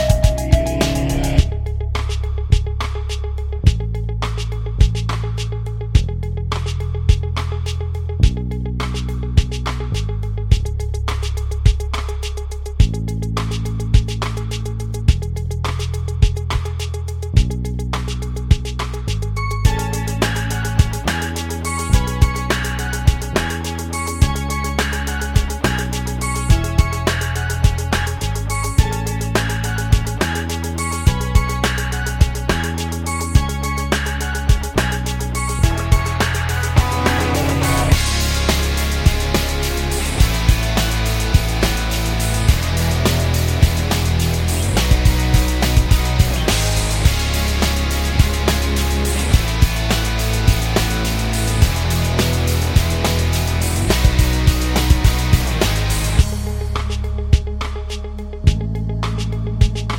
no Backing Vocals Indie / Alternative 3:30 Buy £1.50